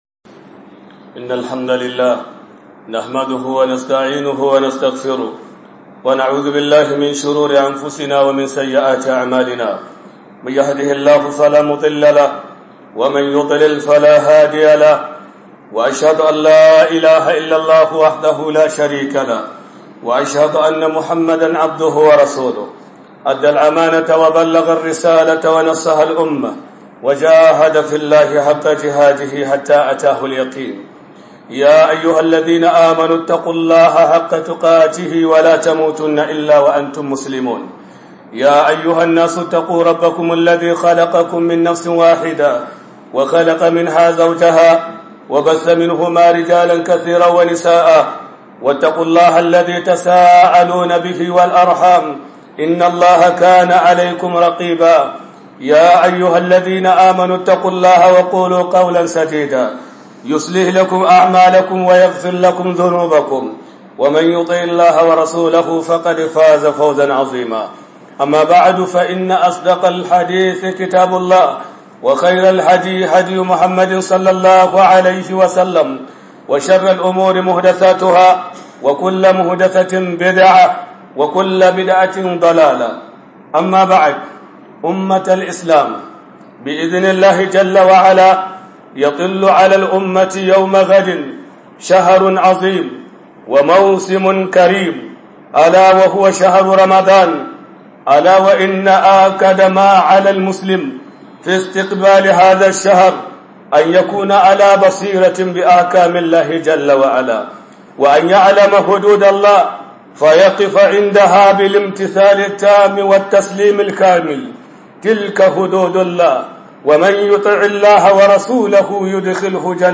خطبة الجمعة في ليدو~1